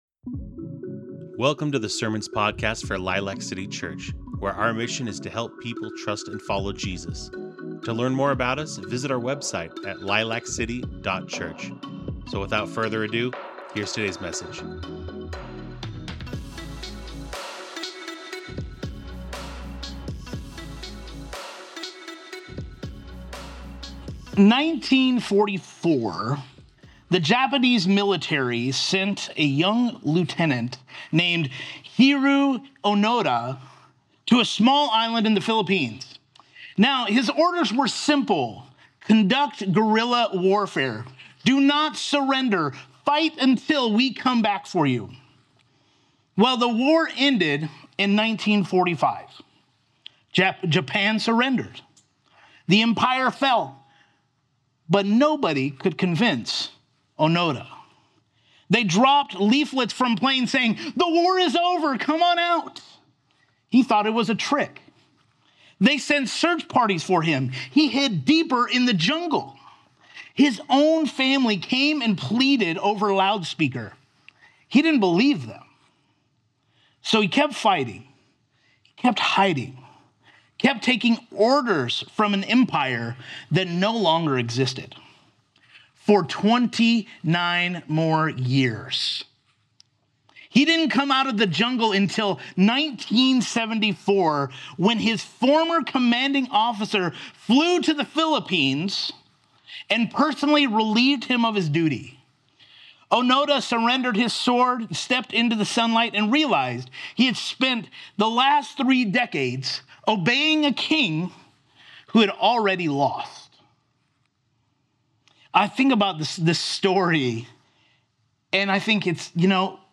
Discover your new identity in Christ and freedom from sin's reign. Romans 6:1-14 sermon from Lilac City Church.